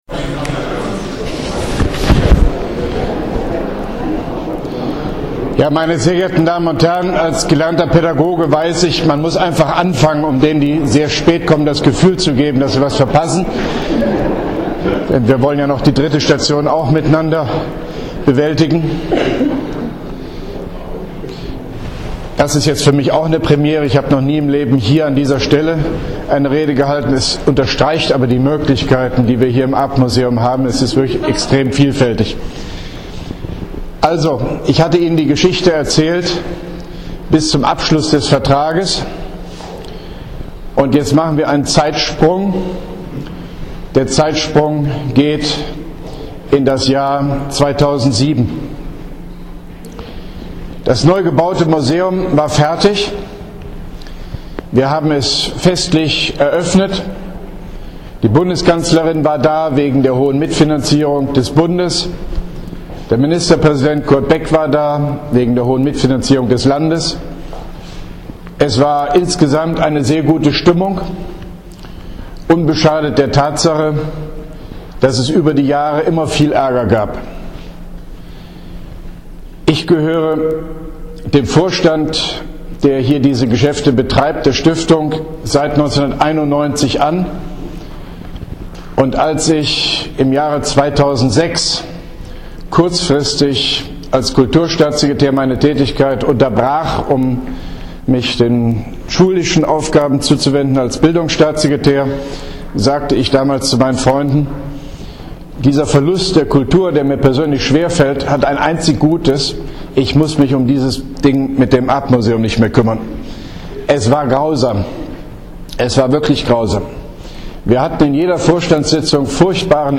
Arp Vortrag Zweite Station